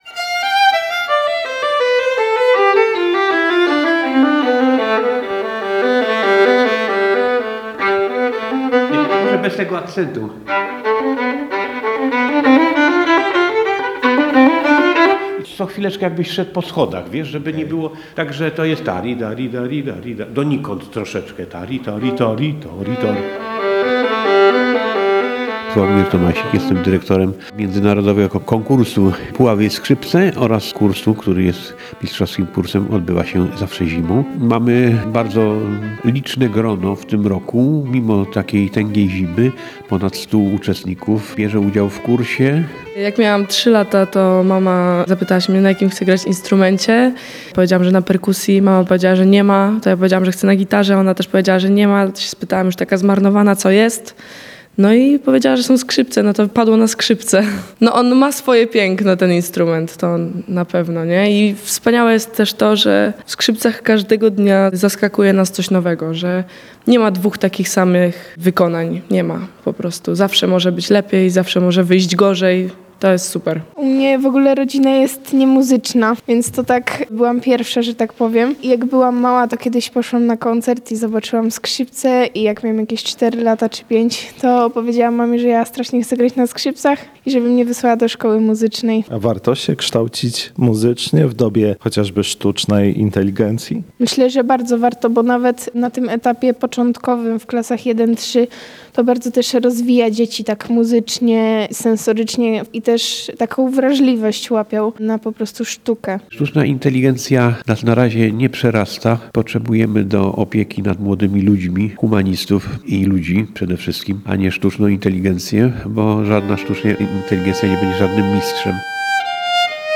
Budynek I Liceum Ogólnokształcącego im. Księcia Adama Jerzego Czartoryskiego w Puławach wypełniają dźwięki altówki i skrzypiec.